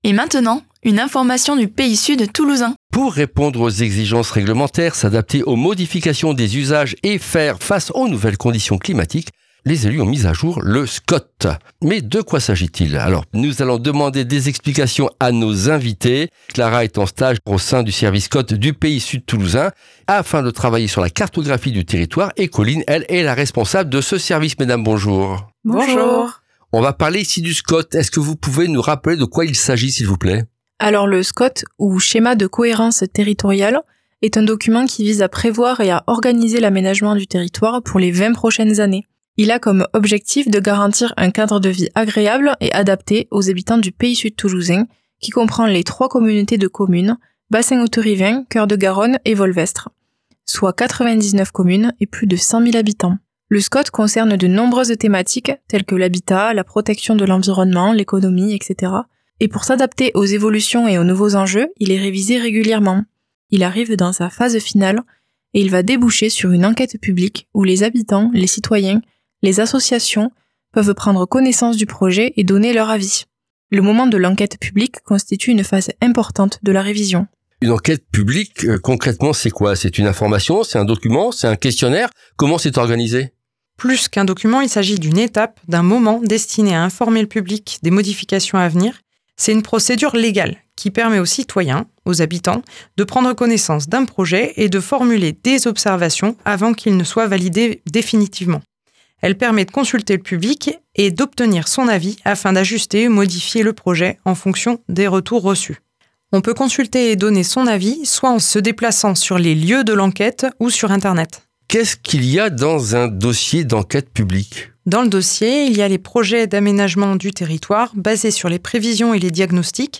Retrouvez ici les brèves capsules radiophoniques de 5 min qui  servent d’introduction